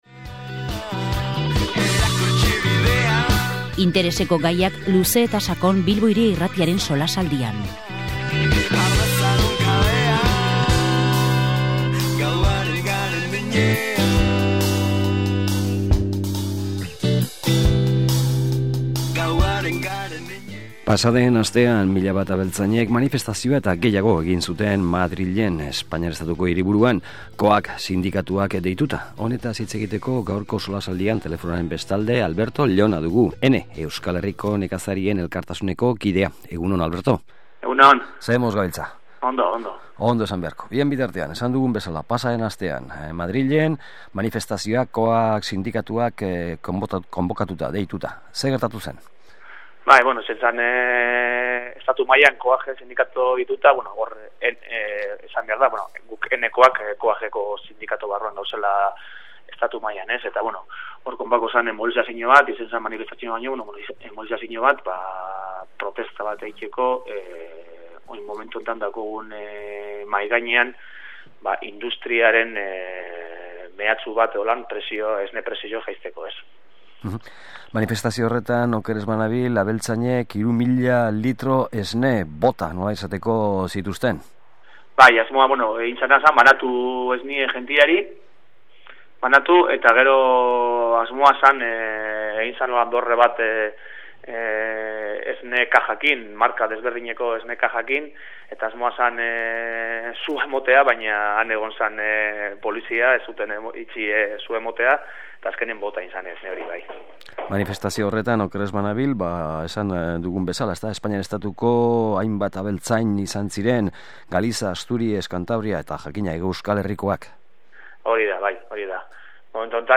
SOLASALDIA: Esnearen prezioa